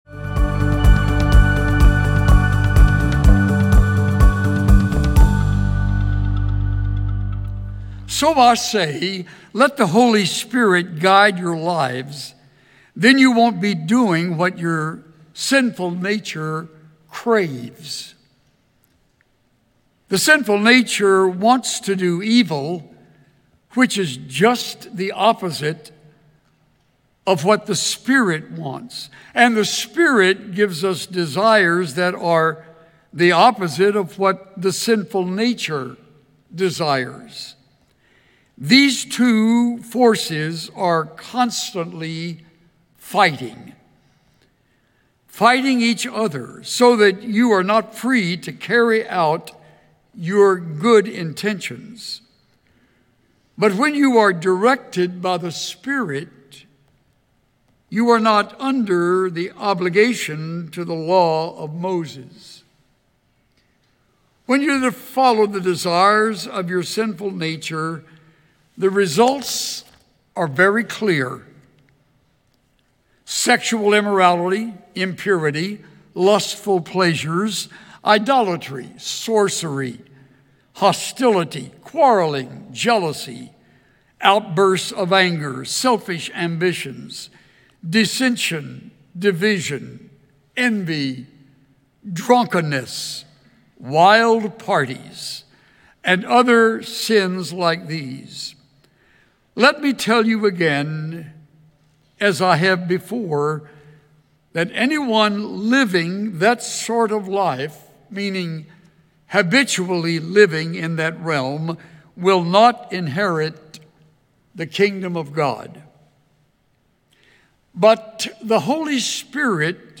Praise the Lord for securing our victory in Jesus. Join us for worship in song led by our Woodwind Quintet and the Gloria Dei Youth Choir, and hear the first message in Pastor Chuck Swindoll’s new…
Listen to Message